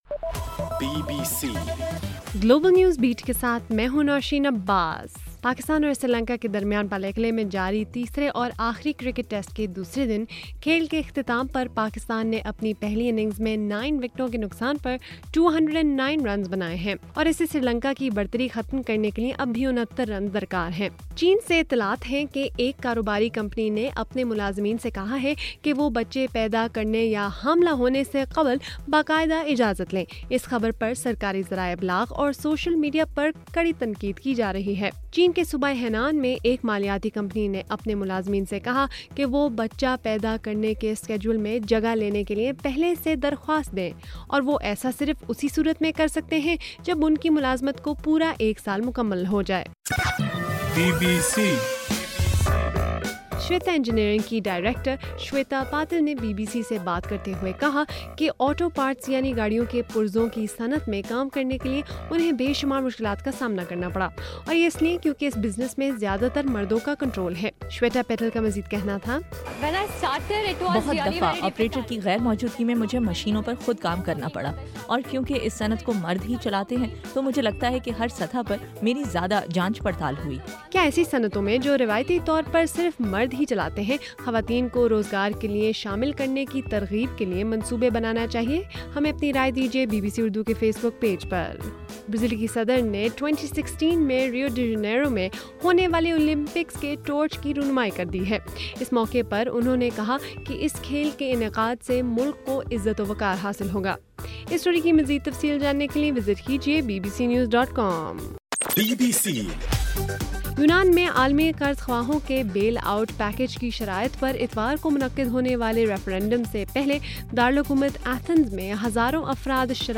جولائی 4: رات 10 بجے کا گلوبل نیوز بیٹ بُلیٹن